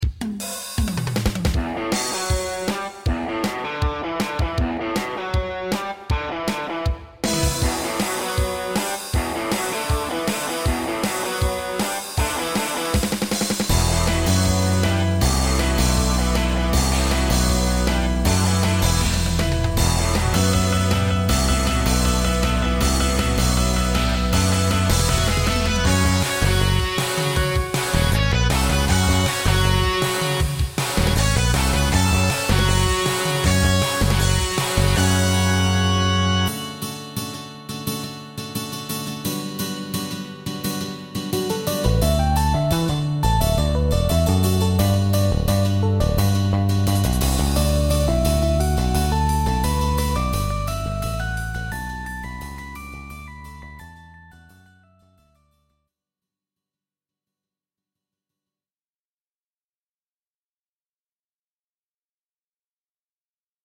具合をみるため、チョコット、お遊びセッションをさせてみました・・・。